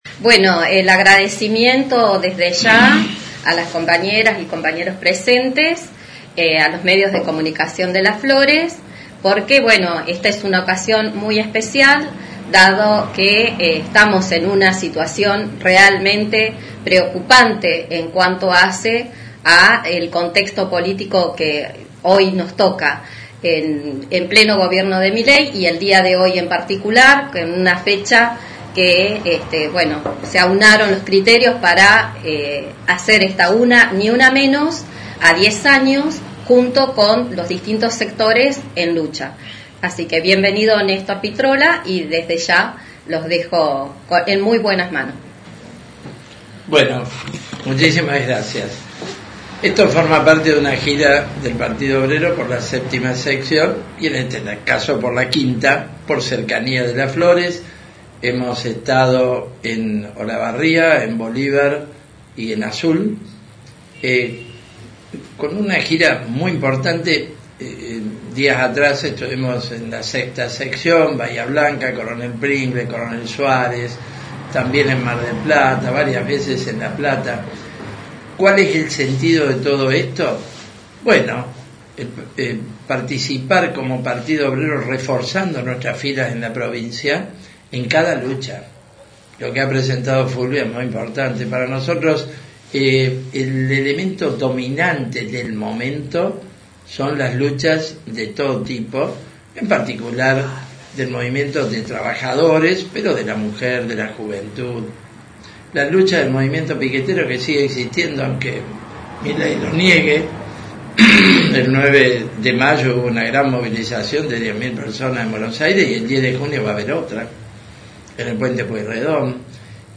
Pitrola dio un panorama a nivel nacional sobre la evolución de la Izquierda, como también dió a conocer sobre su circuito en distintas secciones de la provincia, expresando que su partido todavía no tiene definido ningún candidato para las próximas legislativas. Audio de la conferencia